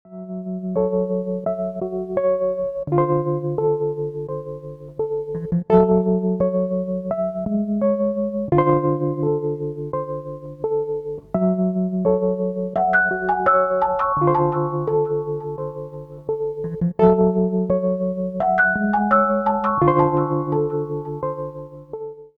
электронная музыка
спокойные
без слов
Electronica
Downtempo
клавишные